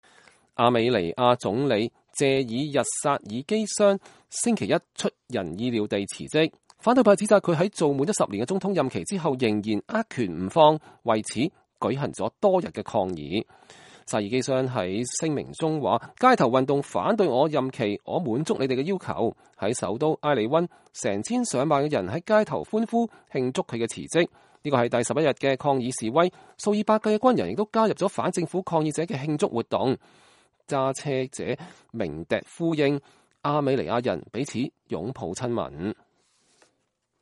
埃里溫居民慶祝亞美尼亞總理謝爾日薩爾基相辭職。